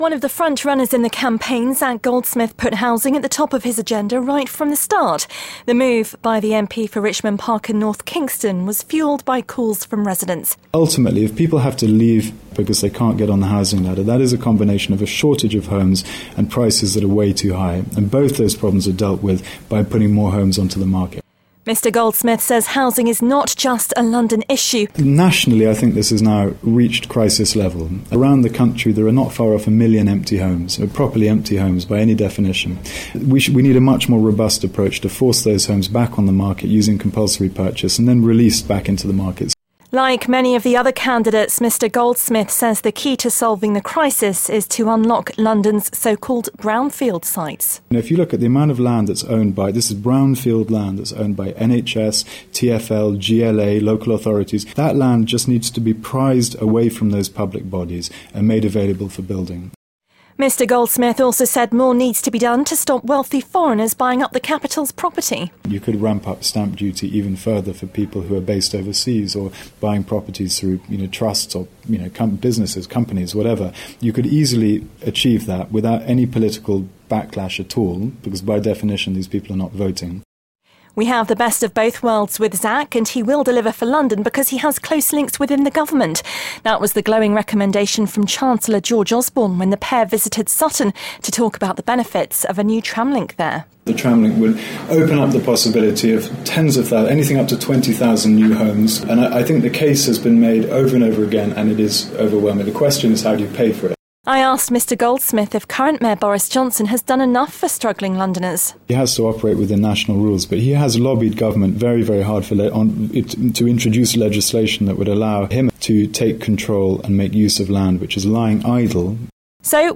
Radio Jackie Housing Interview London Mayor